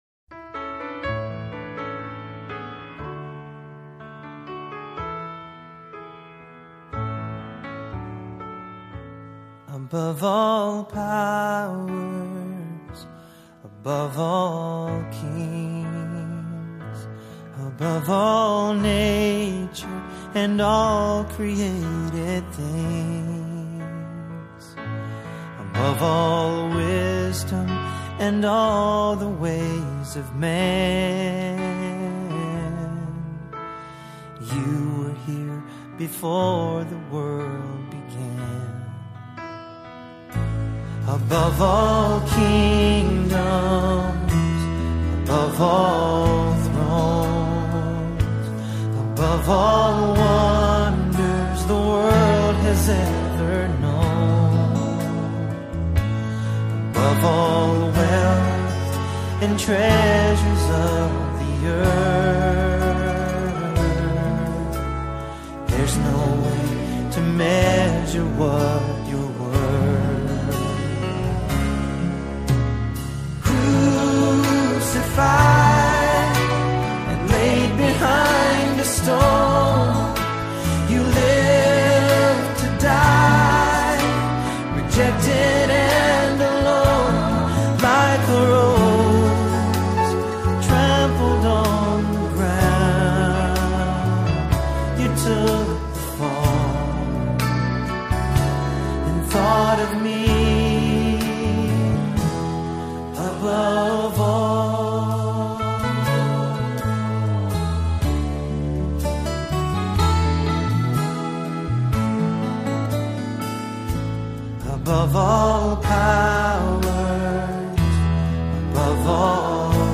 GOSPEL AUDIO SONG & LYRICS